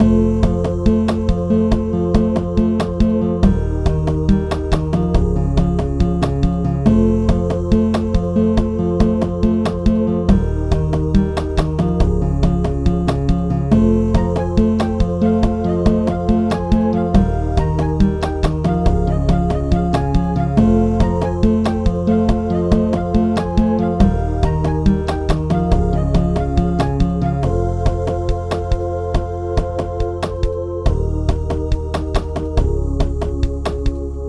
I enjoyed using chopping sounds for the beat!